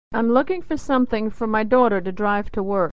Unstressed 'for' is reduced = /fər/